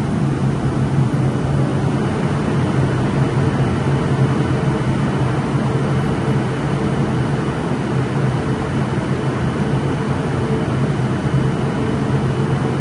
低沉却有力的声响